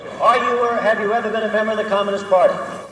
Listen: Former U.S. Representative Joseph McCarthy uses the House Un-American Activities Committee to find communists in America.
Joseph-McCarthy-HUAC.mp3